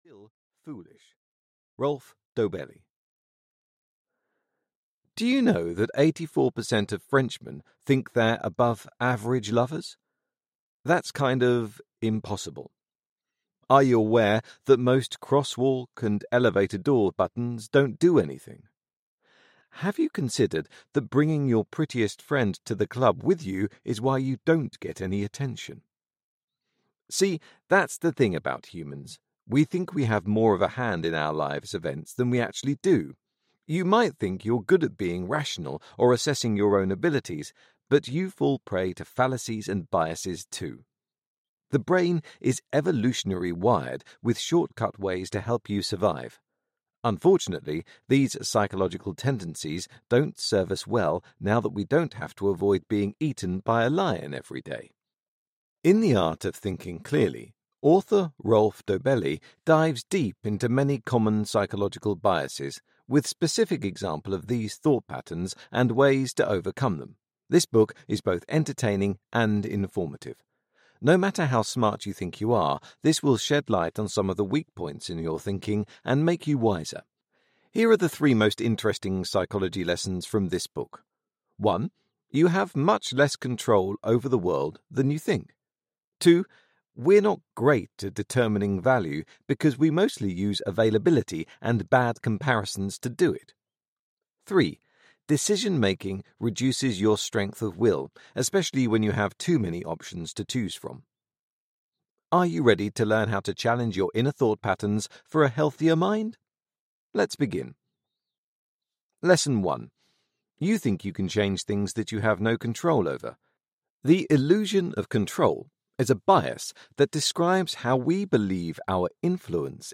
Become the True Leader audiokniha
Ukázka z knihy